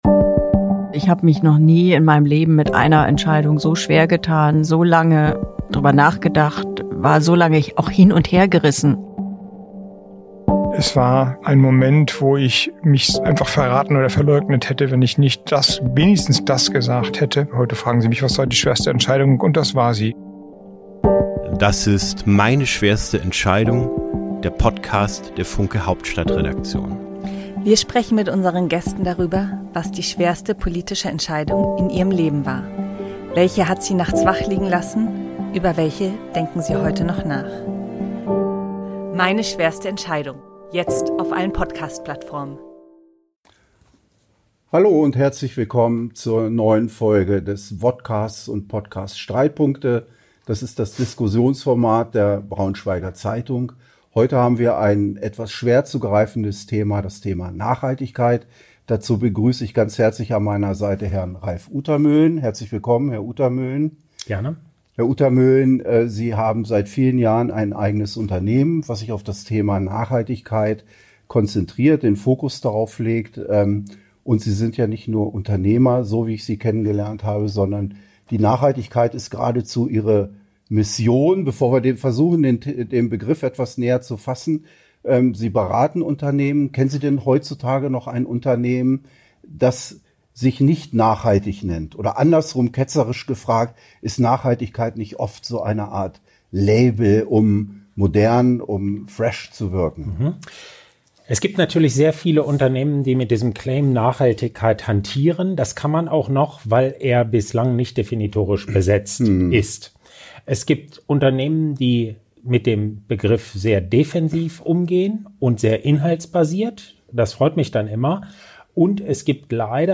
der Diskussions-Podcast der Braunschweiger Zeitung Podcast